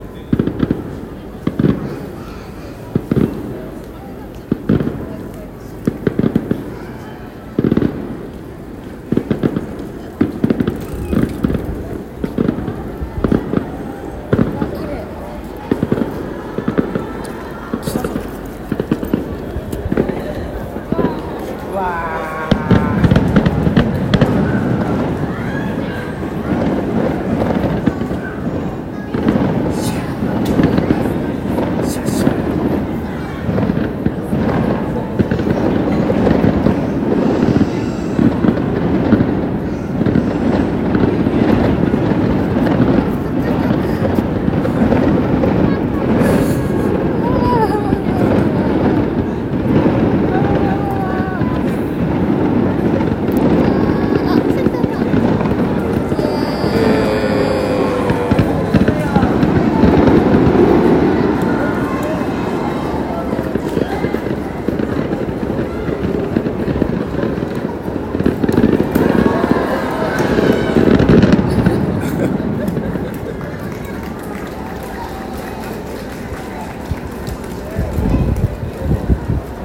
夕方、帰ってくると、隅田川花火大会がはじまりそうだった。
最終的によさそうなところに滞在してフィナーレまで見ていた。
街灯が明るくてなかなか綺麗に撮れなかったので、音でも記録してみる。最後、場が沸いたところが残っていていい。